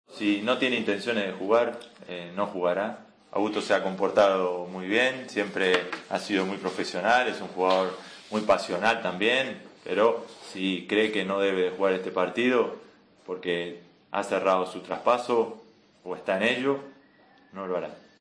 El entrenador del Celta valoró en rueda de prensa la presencia del argentino en el partido ante el Athletic y dejó la papeleta en manos de Augusto Fernández.